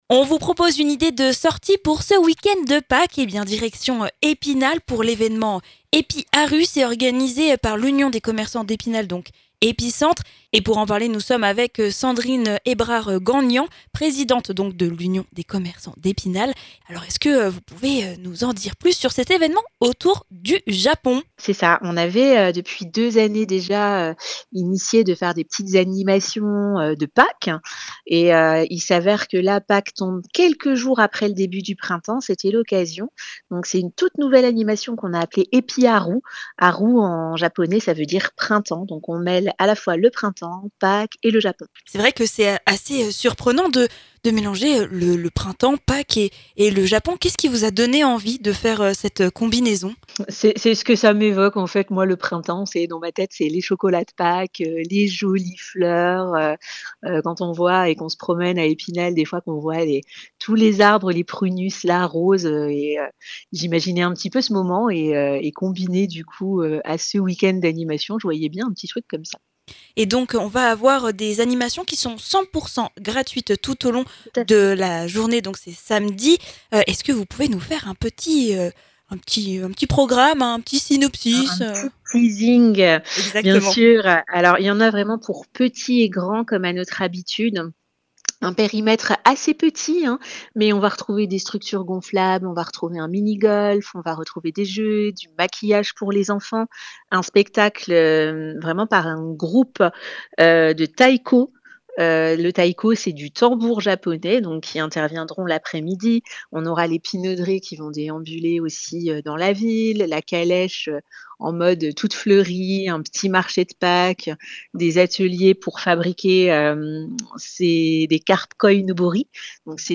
en interview sur VOSGES FM